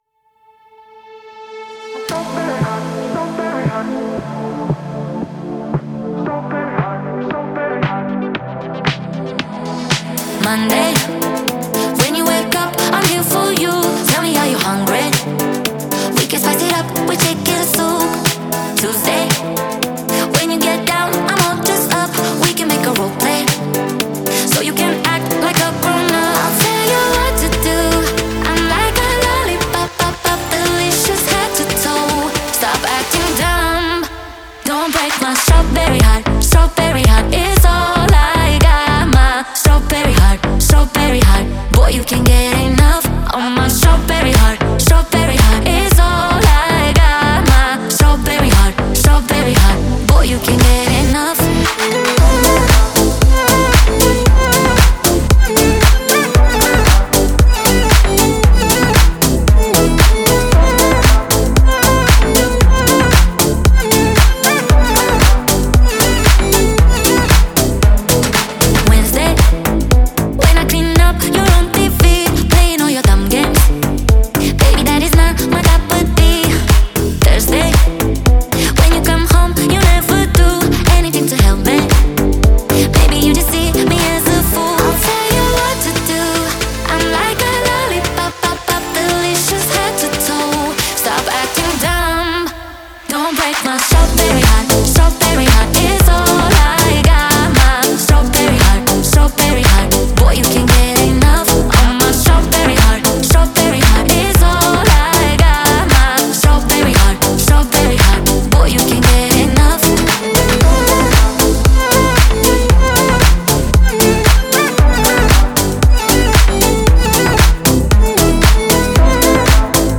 Трек размещён в разделе Зарубежная музыка / Поп / 2022.